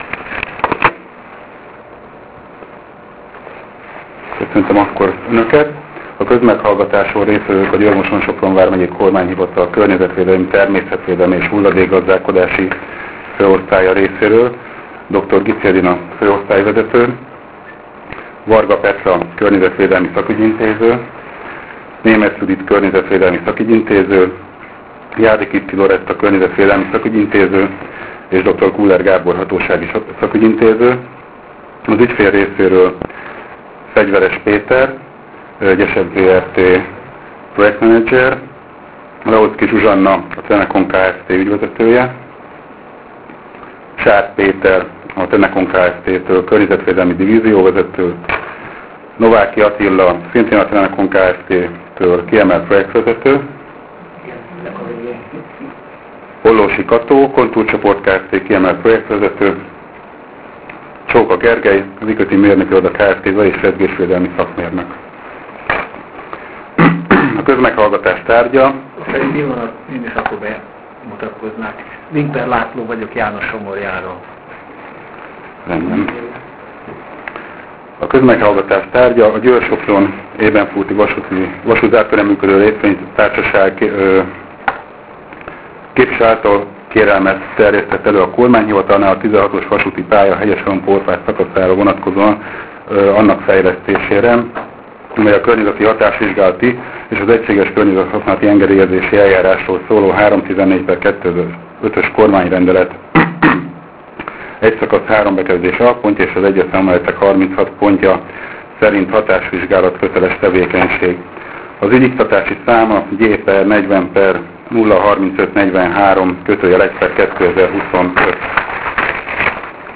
kozmeghallhatas-hangfelvetele-kornyezetvedelmi-osztaly.wav